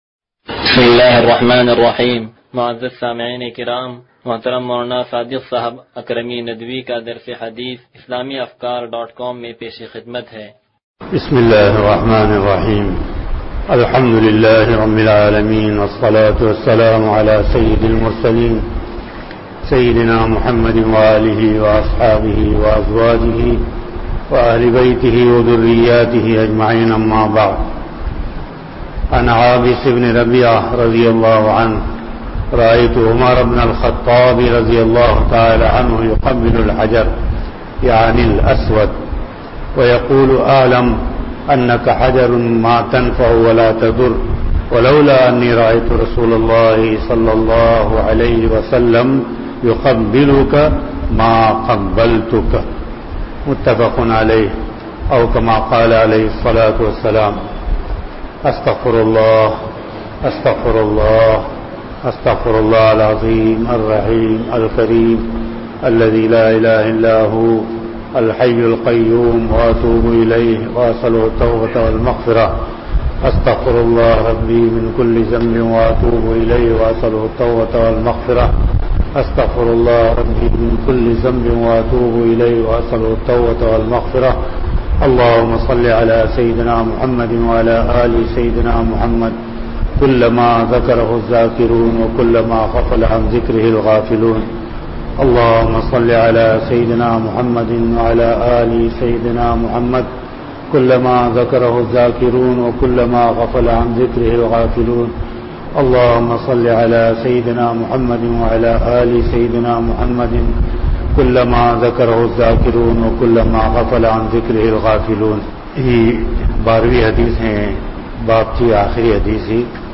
درس حدیث نمبر 0178
(سلطانی مسجد)